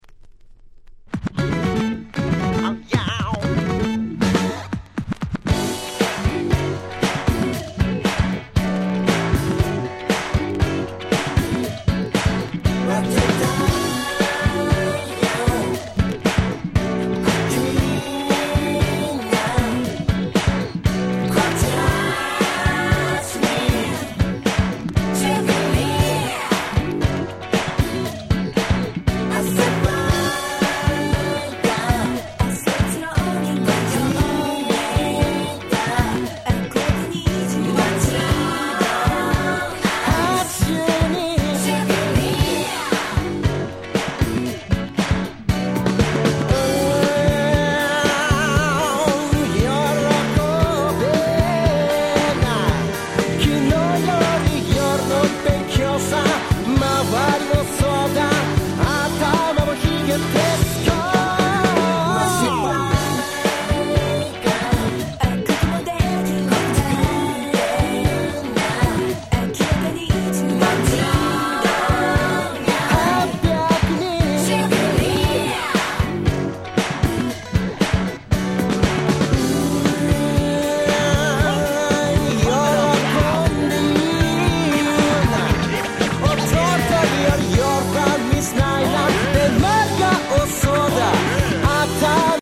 Dance Classicsの往年の名曲達を面白楽しく日本語で替え歌してしまった非常にユーモア溢れるシリーズ！(笑)